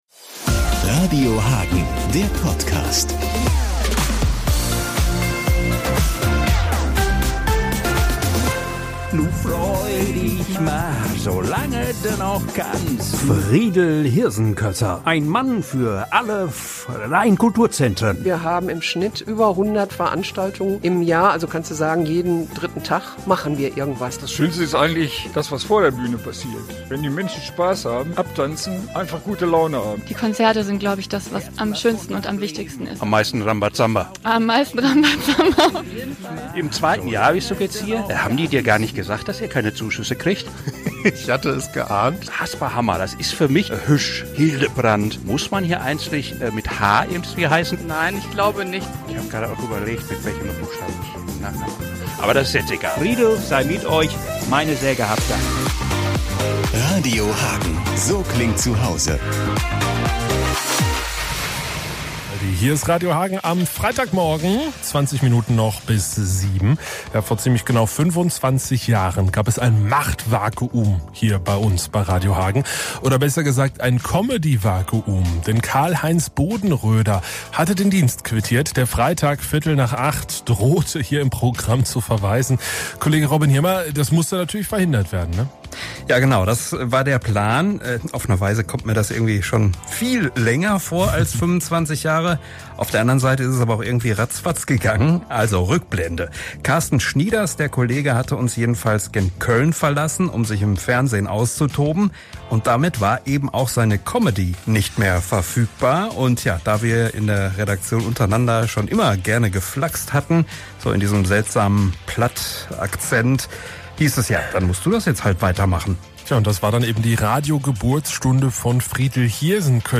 Die fünf freien Kulturzentren wurden besucht - am Ende gab es einen Livestream mit Publikum in der Pelmke. Es geht darum zu zeigen, was mit wenigen Mitteln alles angeboten wird. Außerdem soll die Aktion auf die schwierige Finanzlage der Kulturzentren aufmerksam machen.